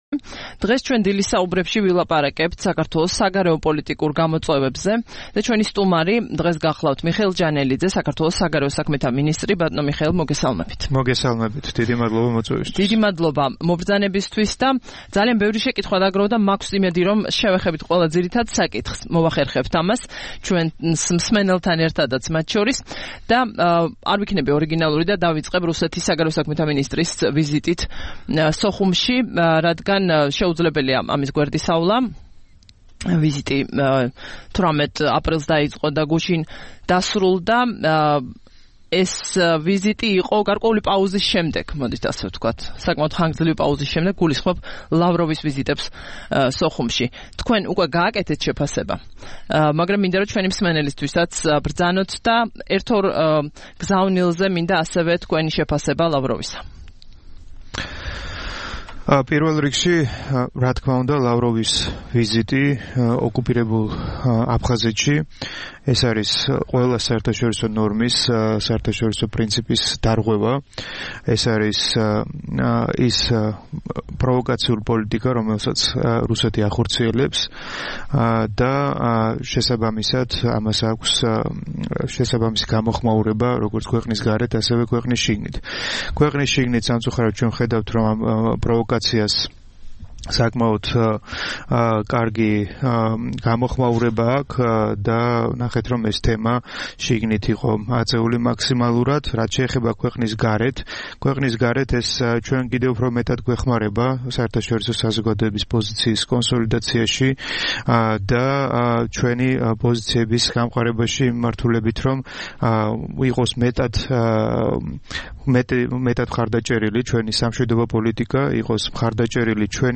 20 აპრილს რადიო თავისუფლების "დილის საუბრების" სტუმარი იყო მიხეილ ჯანელიძე, საქართველოს საგარეო საქმეთა მინისტრი.